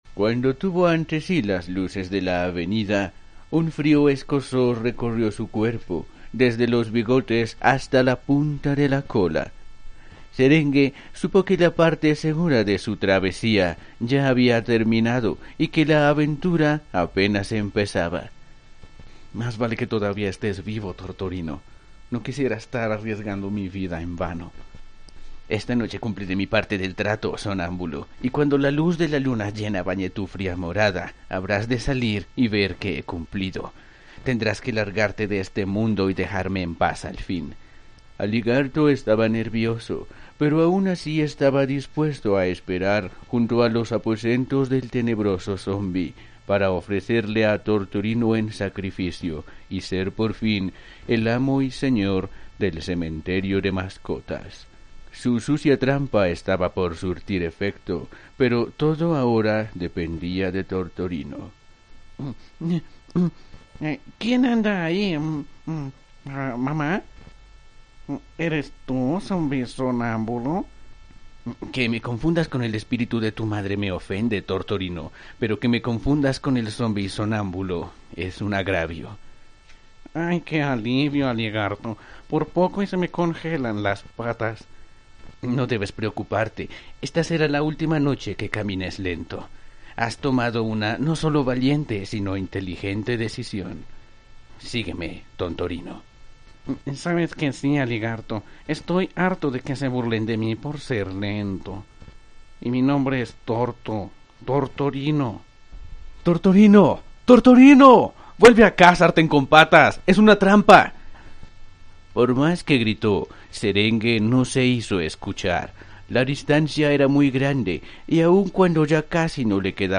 Voice over para e-Learning y documentales, estilo Nat-Geo.
kolumbianisch
Sprechprobe: Industrie (Muttersprache):